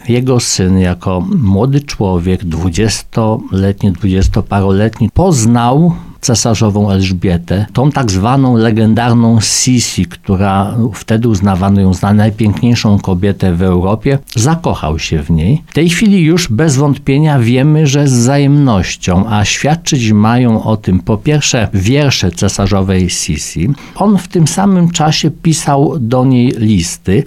Jak mówił w rozmowie z radiem RDN, kiedy odwiedzał znajdującą się również na tamtejszym cmentarzu mogiłę kapitana Wiktoryna Tretera, zainteresował go sąsiedni, zarośnięty mchem nagrobek.